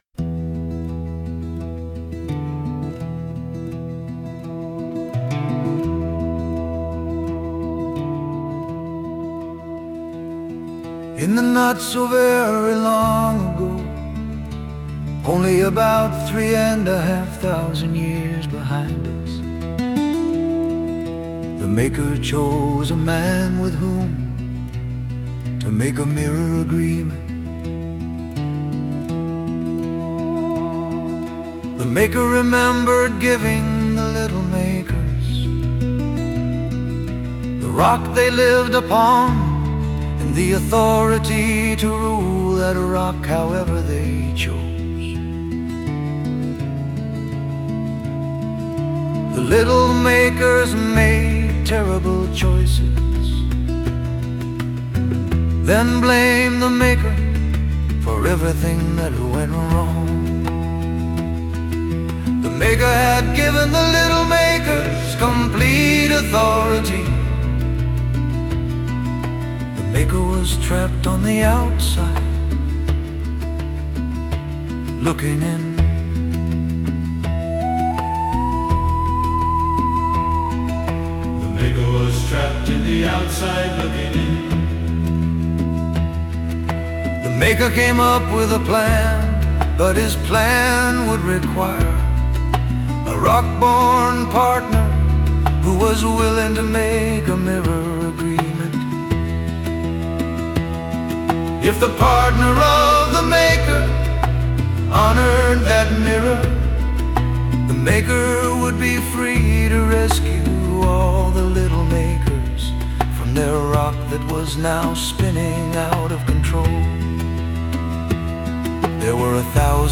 “Way Back in the Long Ago” is a campfire story told by a group of old men.